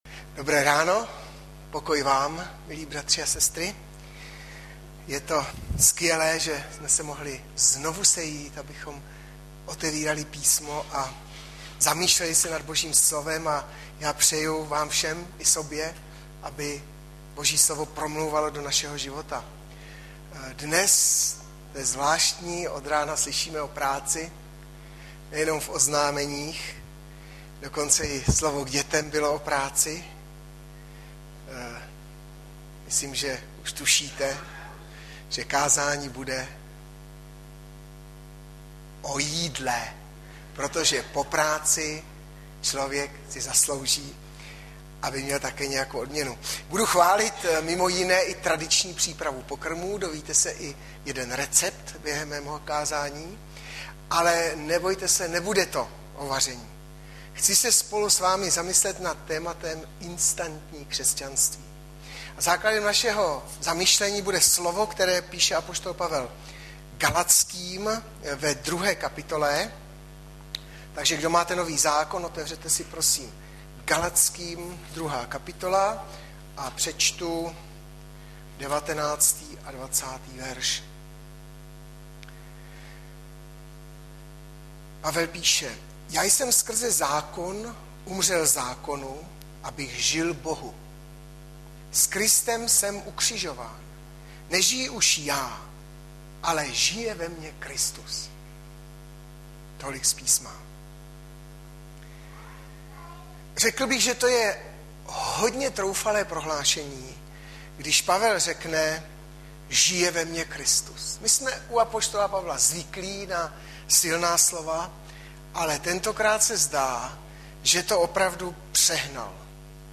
Hlavní nabídka Kázání Chvály Kalendář Knihovna Kontakt Pro přihlášené O nás Partneři Zpravodaj Přihlásit se Zavřít Jméno Heslo Pamatuj si mě  20.05.2012 - INSTANTNÍ KŘESŤAN - Gal 2,19-20 Audiozáznam kázání si můžete také uložit do PC na tomto odkazu.